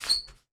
Court Squeak Generic C.wav